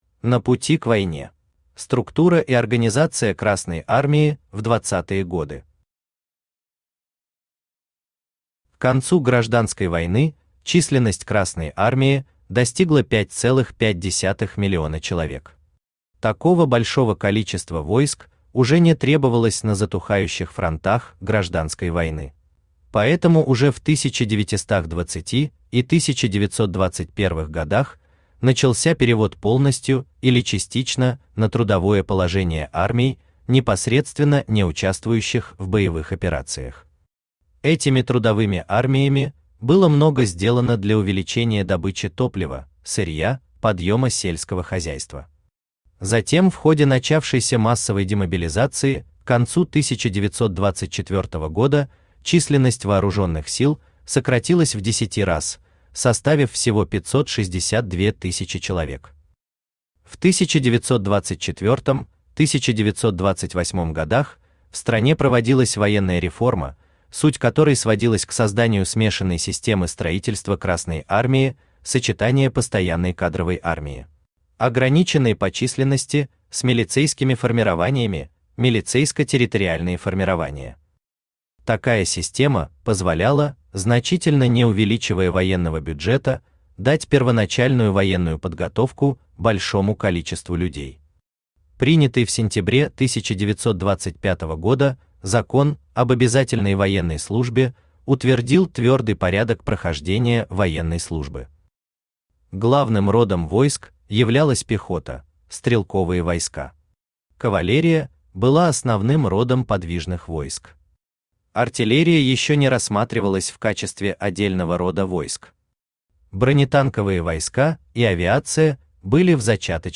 Aудиокнига Идет война народная, священная война Автор Игорь Аркадьевич Родинков Читает аудиокнигу Авточтец ЛитРес.